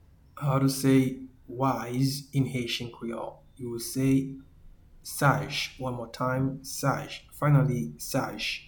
Pronunciation:
Wise-in-Haitian-Creole-Saj.mp3